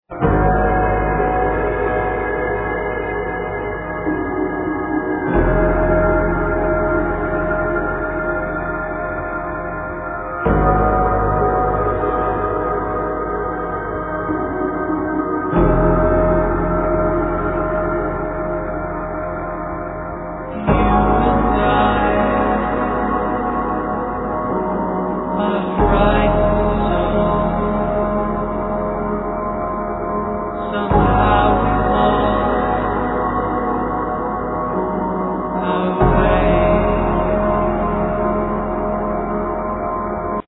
Synthesizer, Voice